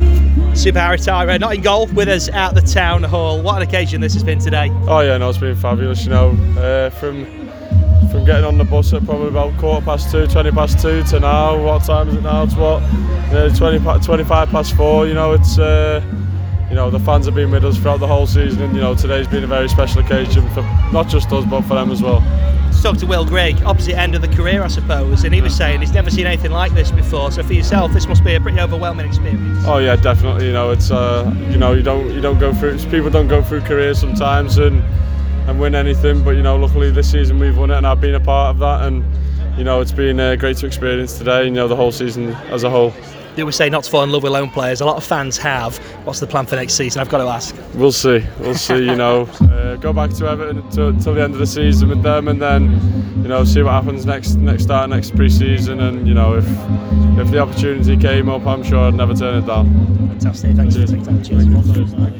Chesterfield celebrated their return to the Football League with an open top bus parade which ended at the Town Hall as thousands of fans joined the team to share in the joy of their amazing National League triumph.